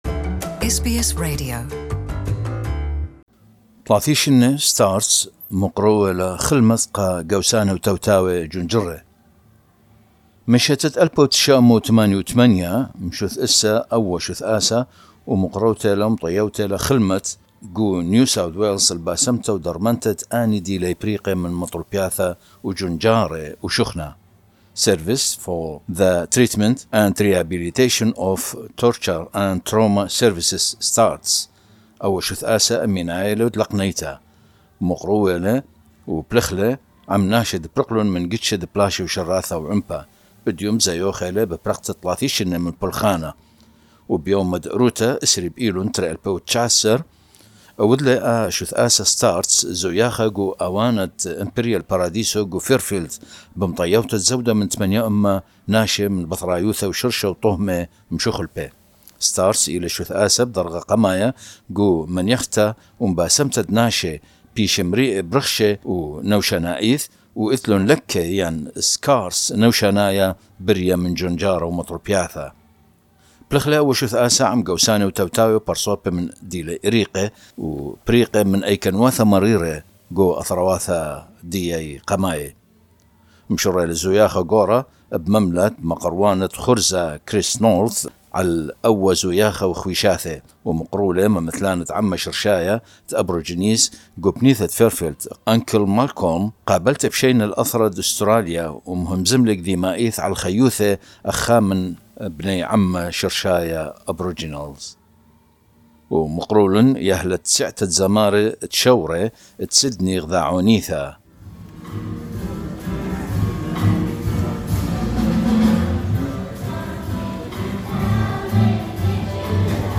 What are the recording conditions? The NSW service for the treatment and rehabilitation of torture and trauma survivors, celebrated 30 years of supporting clients from asylum seekers and refugees who have endured the worst in their life. This is coverage of the celebration held in Fairfield on Sunday 20 September 2019.